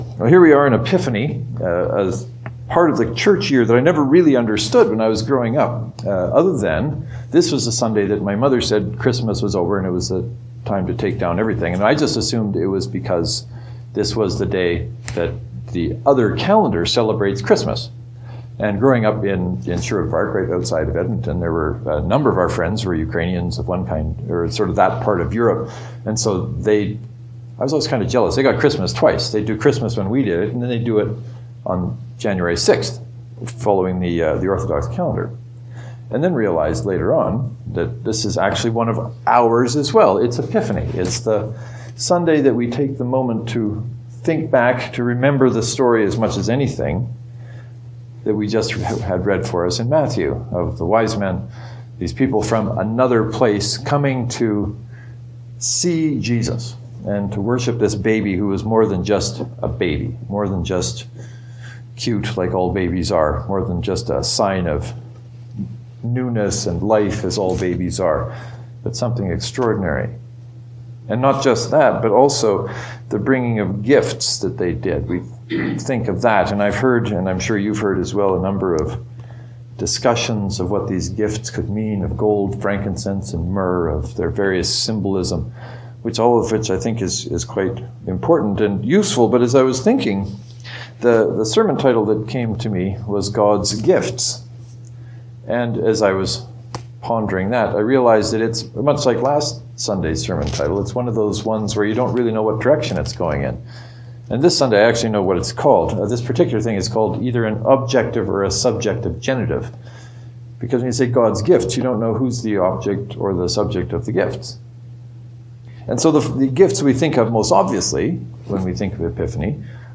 Over the years many of my sermon titles have taken the form of a question which, when things go the way I’m hoping, is answered in the sermon.